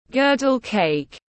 Bánh tráng tiếng anh gọi là girdle-cake, phiên âm tiếng anh đọc là /ˈɡɜː.dəl keɪk/
Girdle-cake /ˈɡɜː.dəl keɪk/